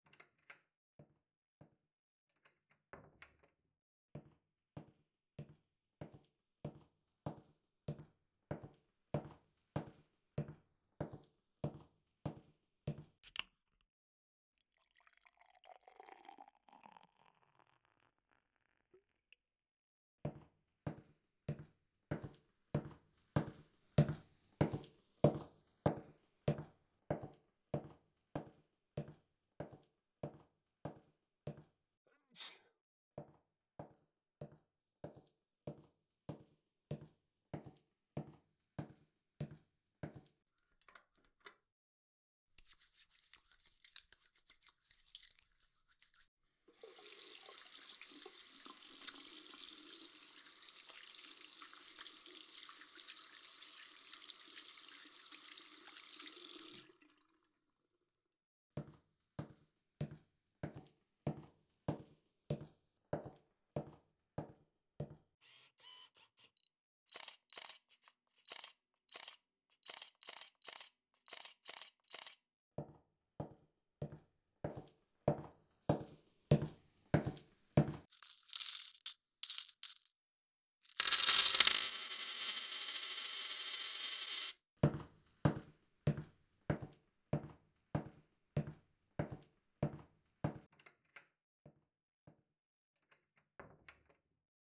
Binaural Synthesis (Virtual 3D Audio) Samples: